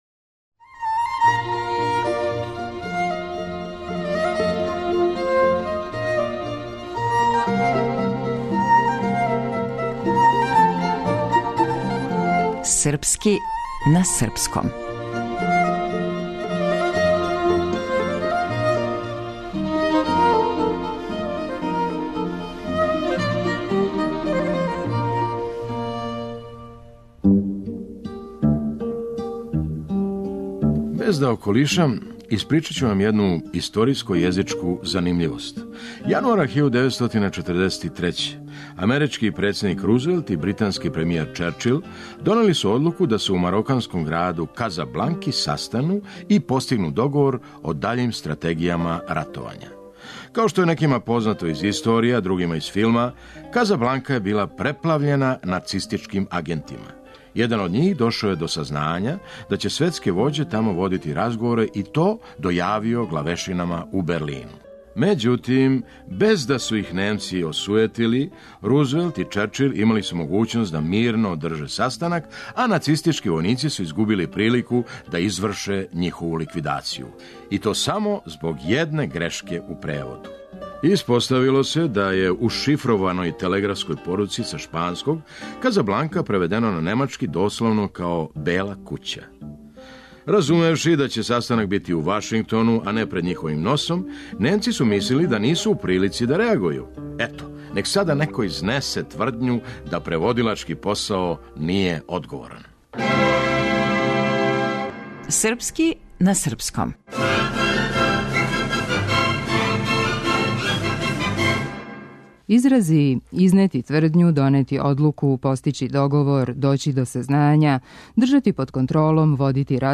Драмски уметник